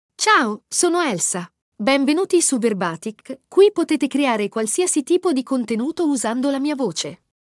ElsaFemale Italian AI voice
Elsa is a female AI voice for Italian (Italy).
Voice sample
Listen to Elsa's female Italian voice.
Elsa delivers clear pronunciation with authentic Italy Italian intonation, making your content sound professionally produced.